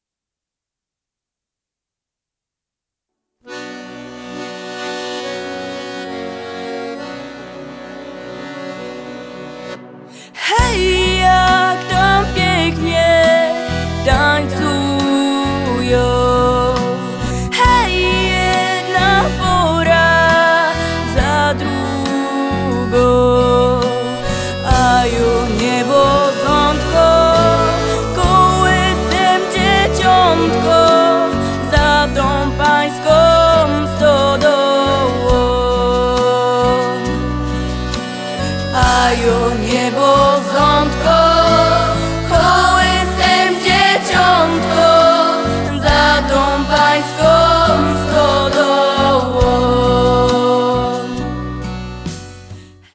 Podkład muzyczny akordeonowy - studyjny.